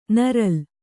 ♪ naral